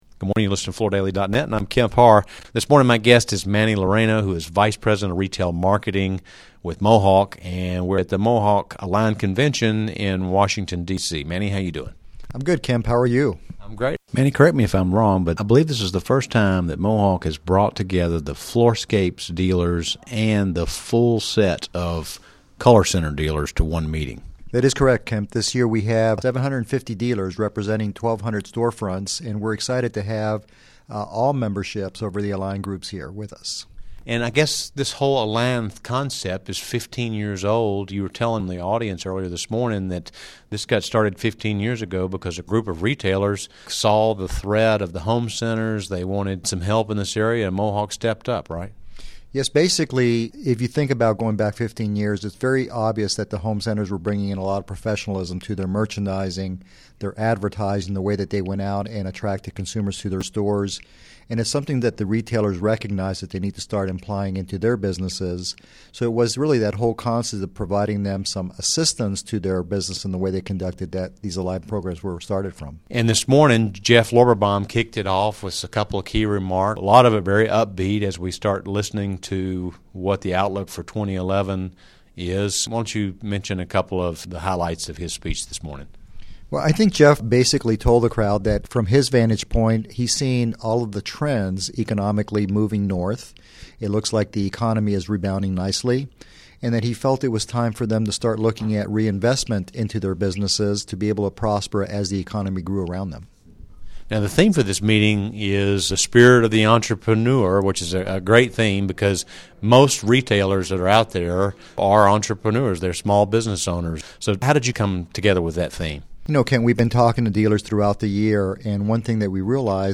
Listen to the interview to hear more about its new product introductions, key speakers and education focus for 2011.